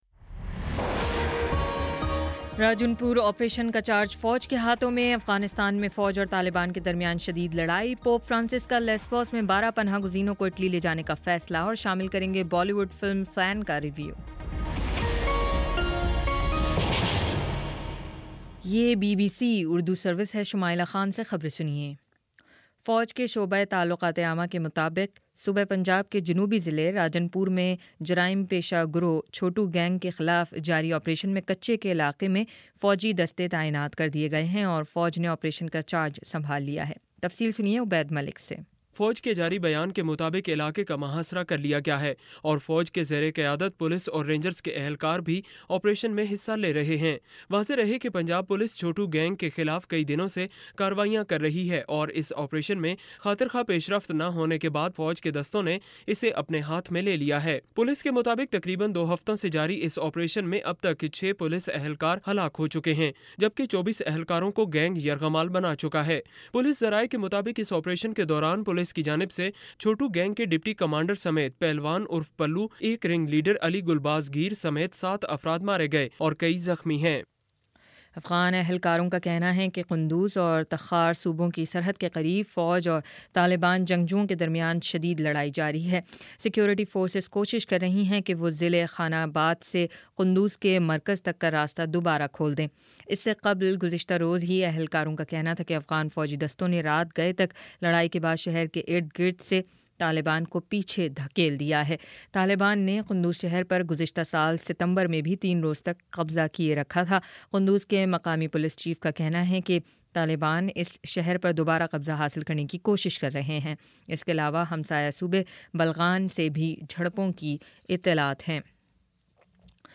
اپریل 16: شام سات بجے کا نیوز بُلیٹن